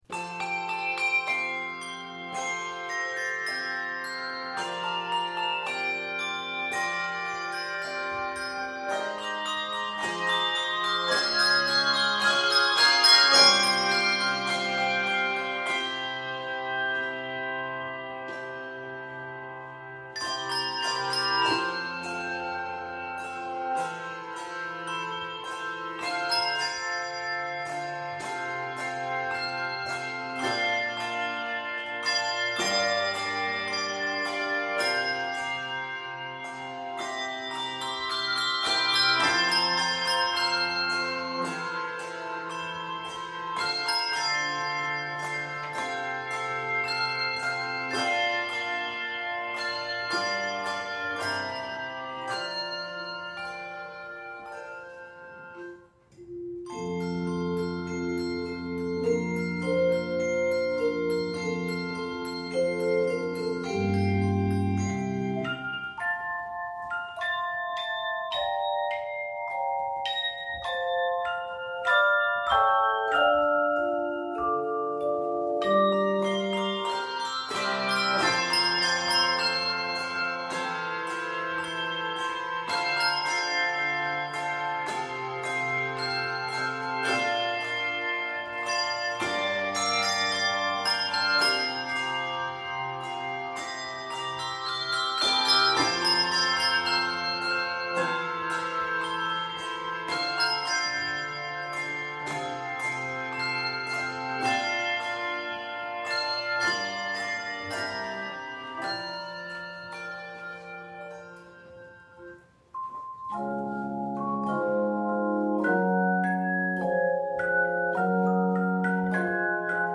a gentle, yet expansive setting